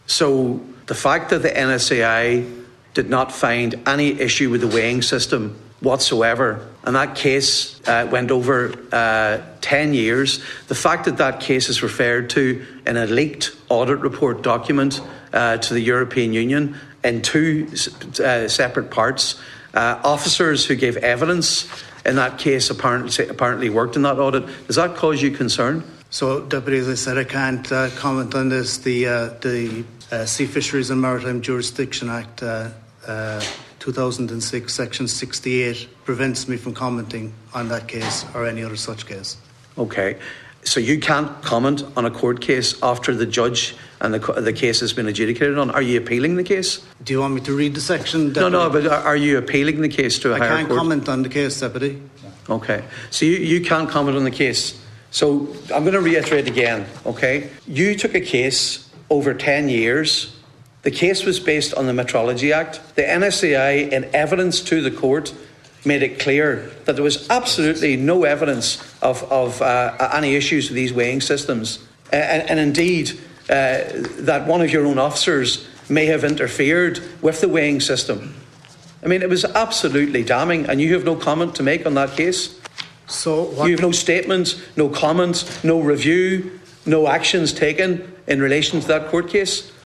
At a meeting of the joint Oireachtas Committee on Fisheries and Maritime Affairs today, Donegal Deputy Pádraig Mac Lochlainn questioned the Sea Fisheries Protection Agency about the conclusion of the case following a 10 year investigation.
Deputy Mac Lochlainn asked SFPA Chair Paschal Hayes if there will be a review into the case……………